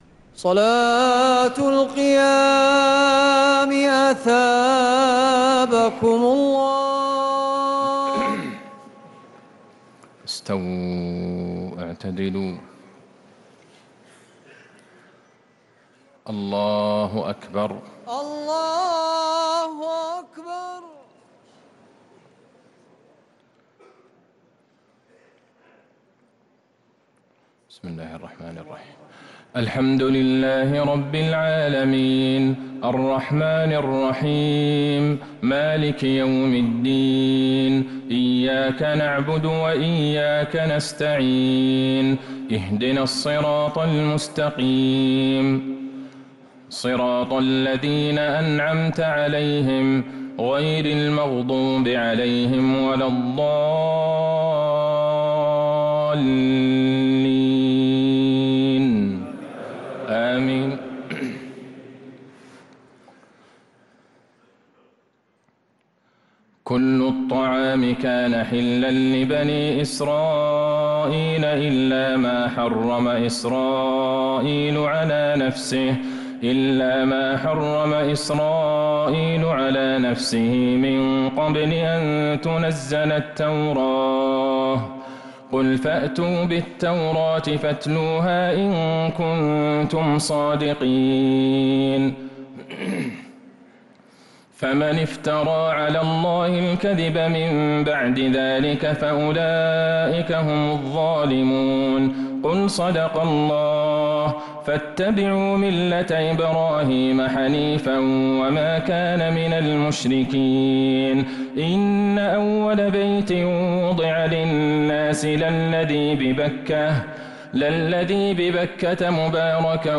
تراويح ليلة 5 رمضان 1447هـ من سورة آل عمران (93-158) | Taraweeh 5th night Ramadan 1447H > تراويح الحرم النبوي عام 1447 🕌 > التراويح - تلاوات الحرمين